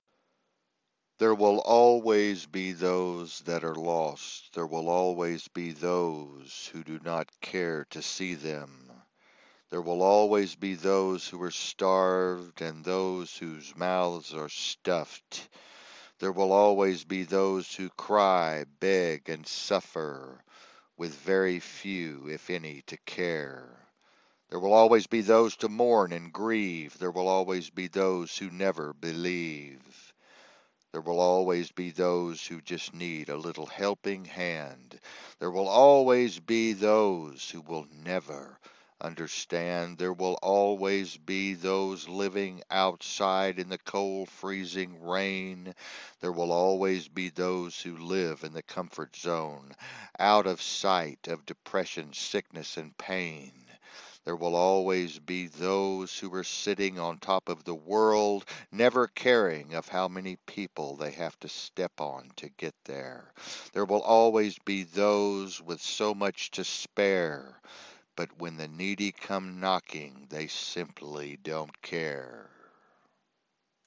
A Spoken Word Piece